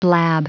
Prononciation du mot : blab